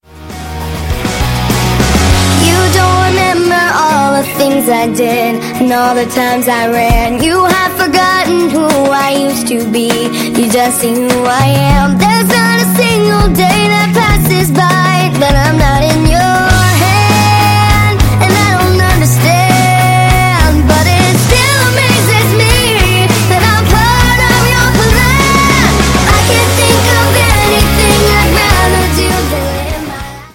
Performance, aktuellen Charts-Pop;
• Sachgebiet: Pop